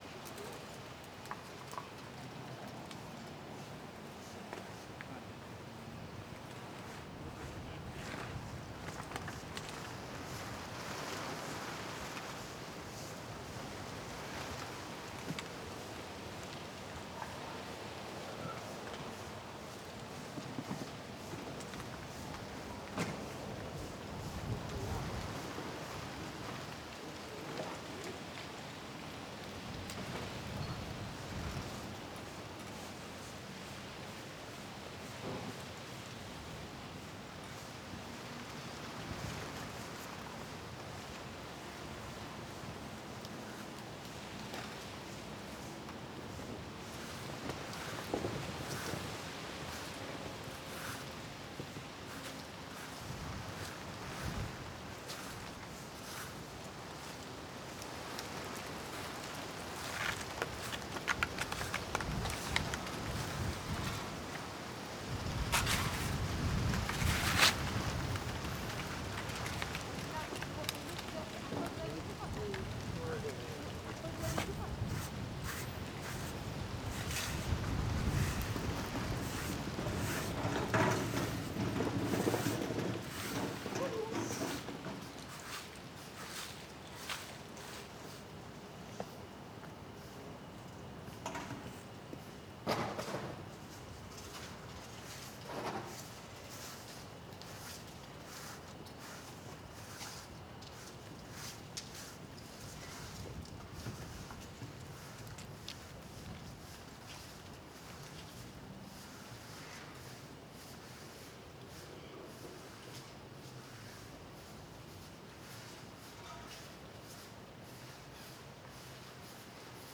Ambiente de cidade pequena mais longe galo e vozes Ambiente externo
Galo
Ruído grave , Rumble cidade São Domingos, Goiás Stereo